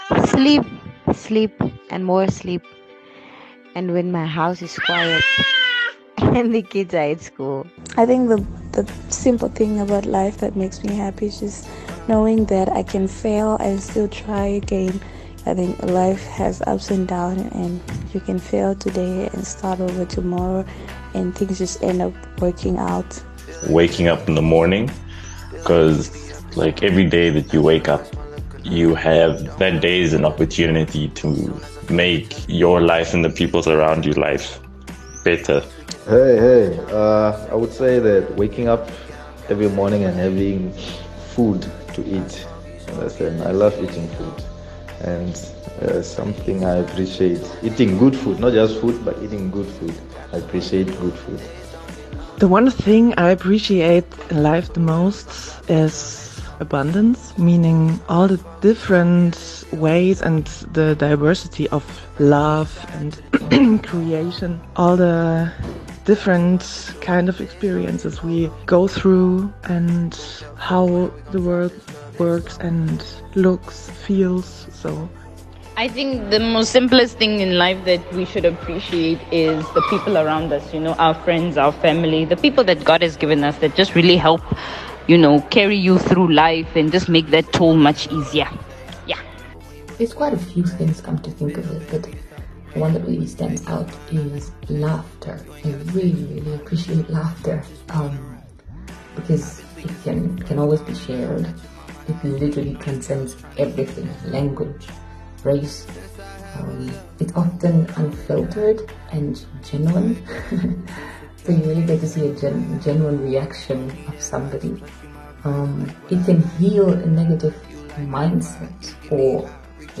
We asked the listeners what they appreciated about life and what makes them happy and this is what they had to say..